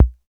KIK CR78 K.wav